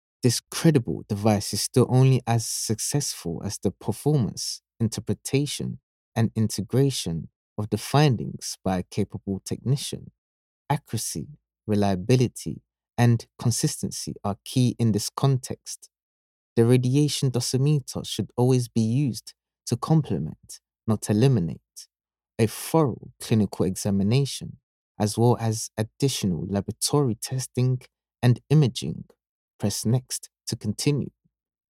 Explainer & Whiteboard Video Voice Overs
English (Caribbean)
Adult (30-50) | Yng Adult (18-29)